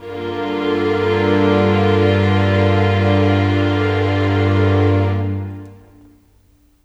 Pad Gmin7 01.wav